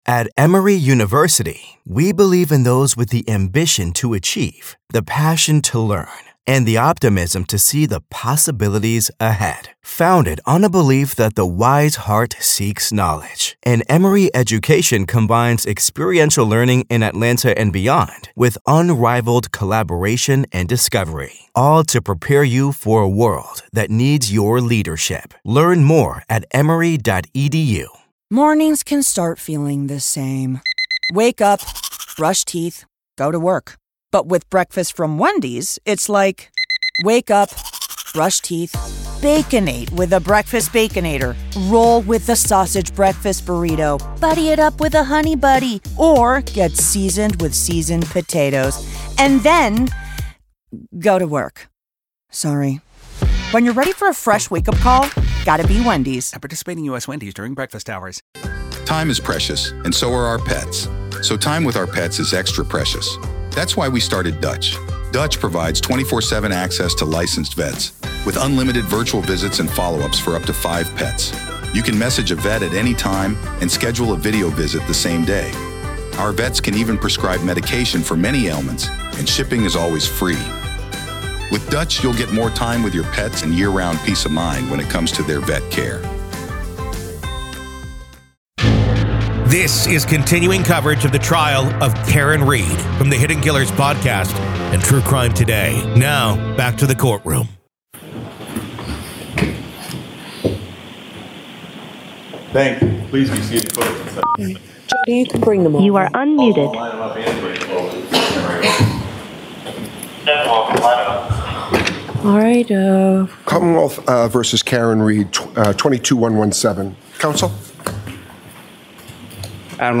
The Trial of Karen Read: Boyfriend Cop Murder Trial – MA v. Karen Read Day 11 Part 1
Welcome to a special episode of "The Trial of Karen Read," where today, we find ourselves inside the courtroom of the case against Karen Read.